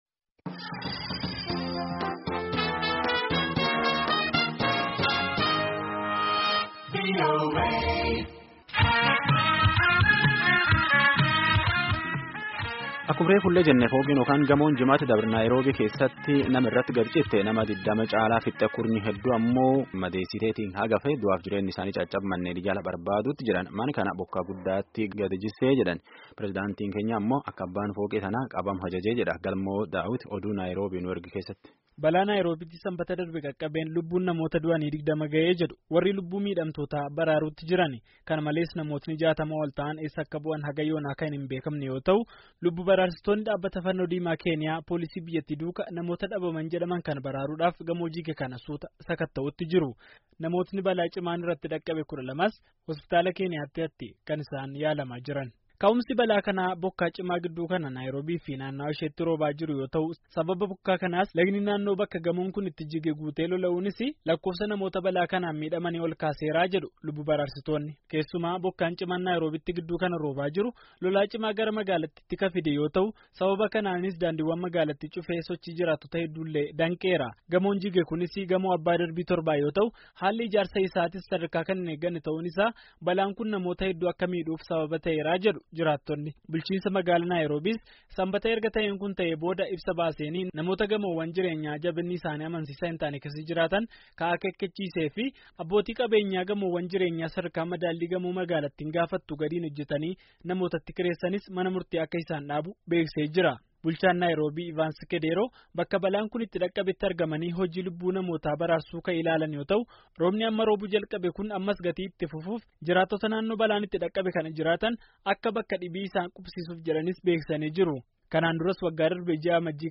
gabaasa itti aanu Naayiroobiirraa gabaasa itti aanu nuuf ergeera.